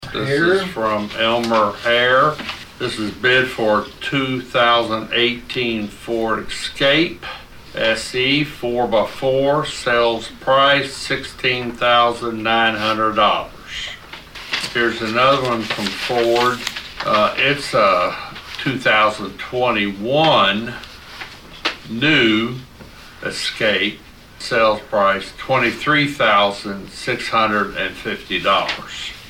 Presiding Commissioner Kile Guthrey Jr. read off the bids from Elmer Hare.